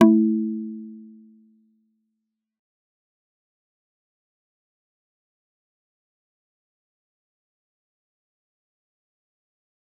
G_Kalimba-A3-f.wav